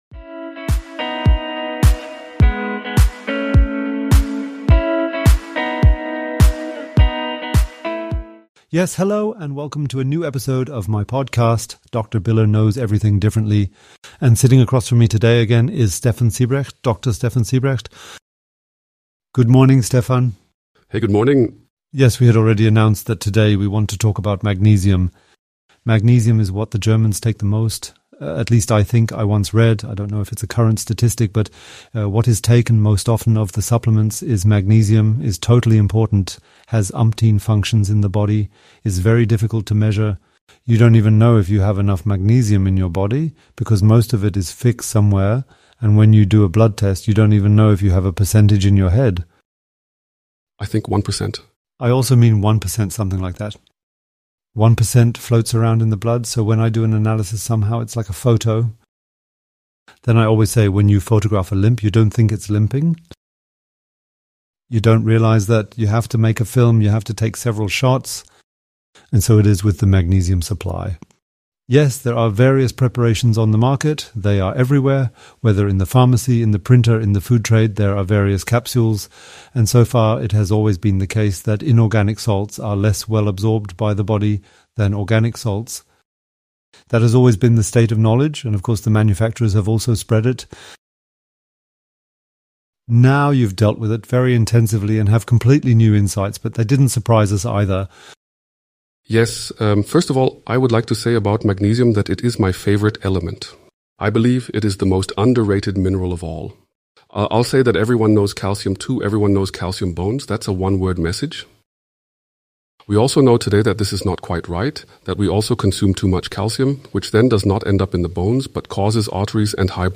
This is the AI-assisted translation of the 33st episode: